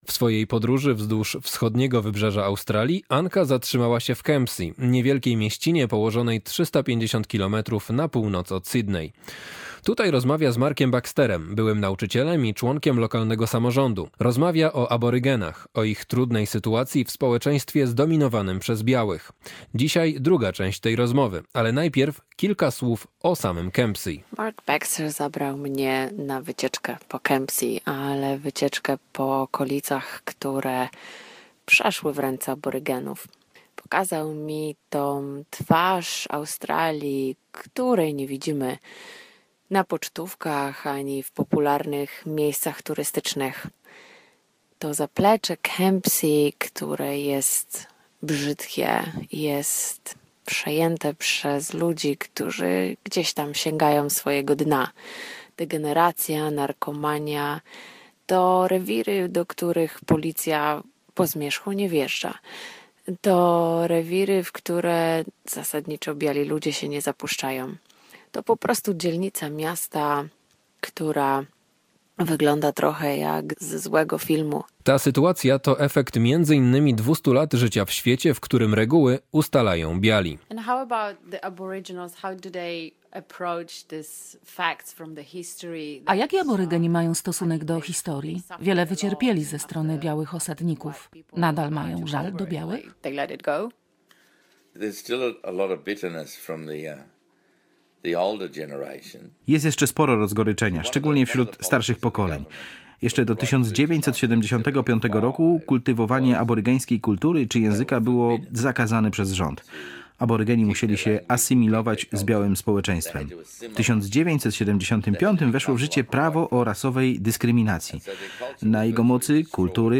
Druga część rozmowy o trudnej historii rdzennych mieszkańców Australii. Opowiada Mark Baxter - były nauczyciel i członek lokalnego samorządu w miasteczku Kempsey.